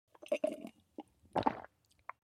دانلود آهنگ آب 10 از افکت صوتی طبیعت و محیط
جلوه های صوتی
دانلود صدای آب 10 از ساعد نیوز با لینک مستقیم و کیفیت بالا